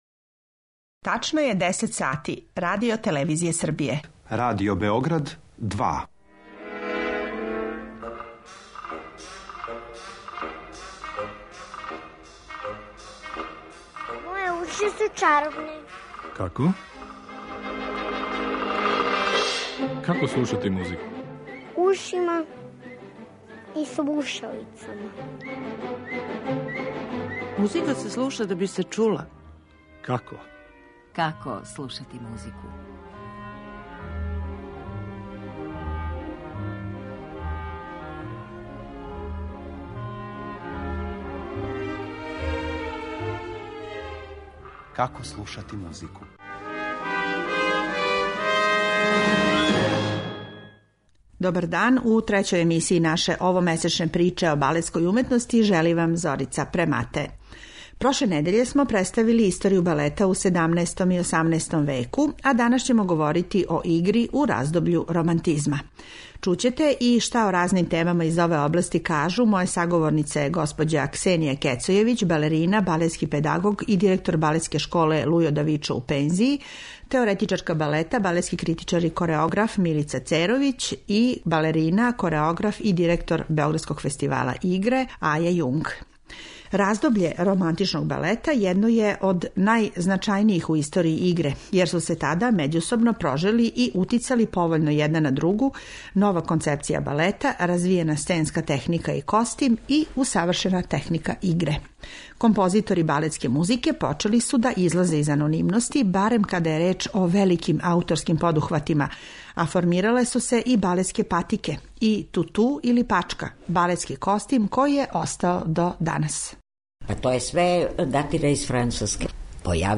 У емисији ћете моћи да чујете и одабране одломке балета из пера Адолфа Адама, Леа Делиба и Петра Иљича Чајковског.